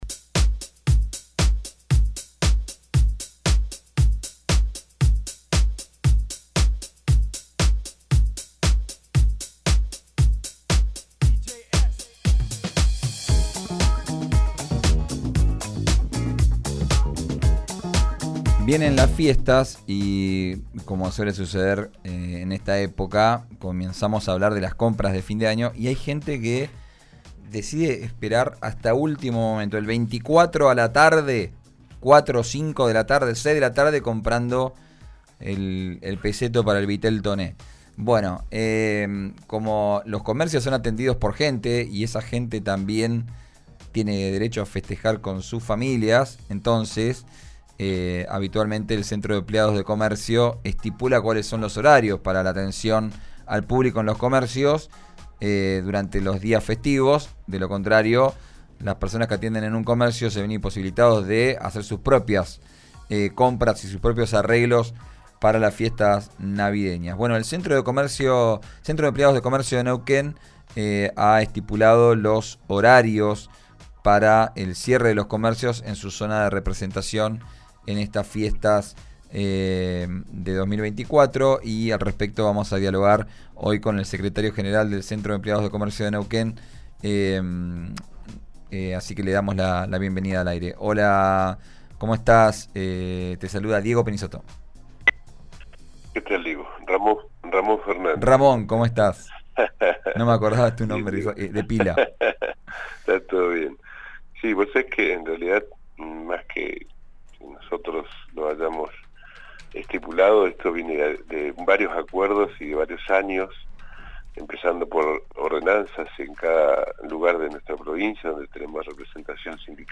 en diálogo con «Vos al aire»